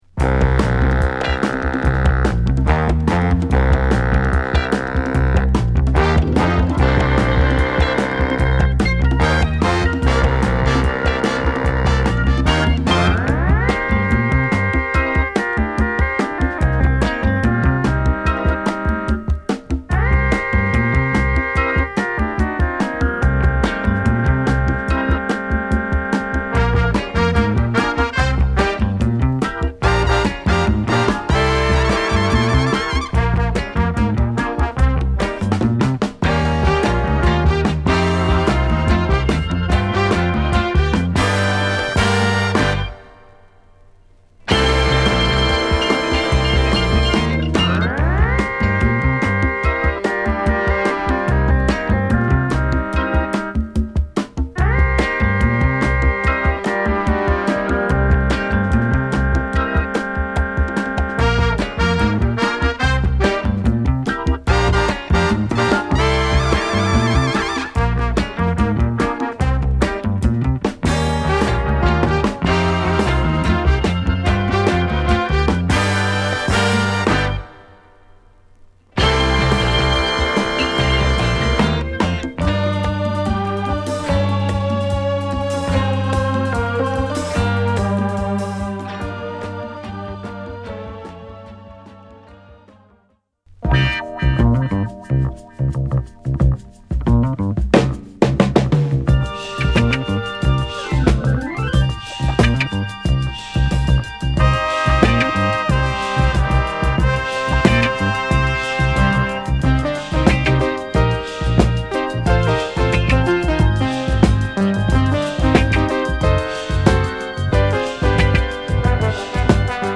ポップな物から渋いジャズ・ファンクまでを披露！